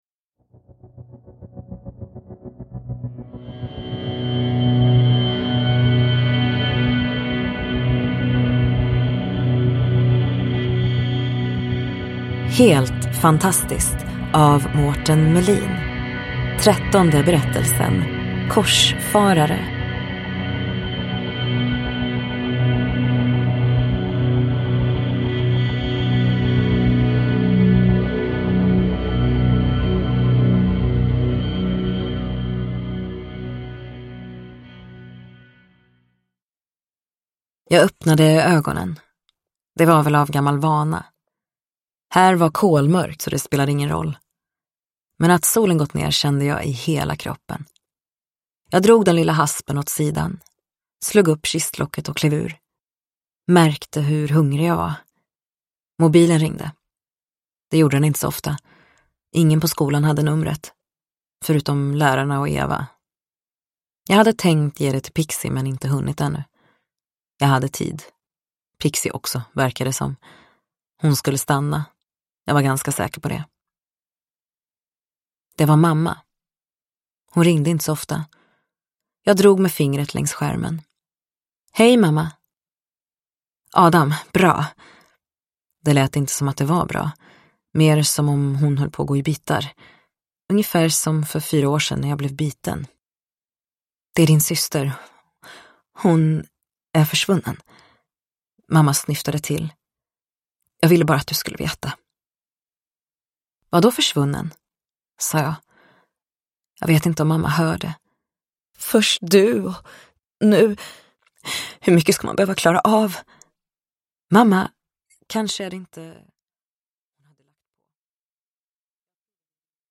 Korsfarare : en novell ur samlingen Helt fantastiskt – Ljudbok – Laddas ner